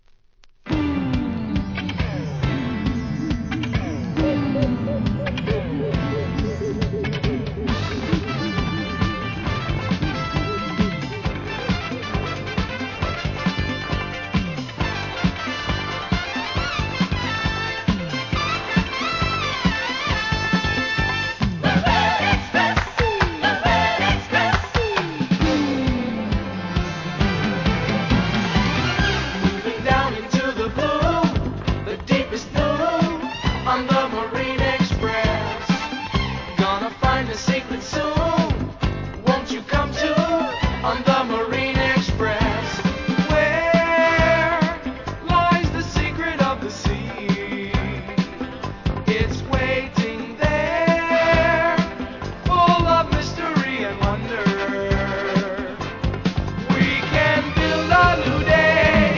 SOUL/FUNK/etc...
爽快DISCO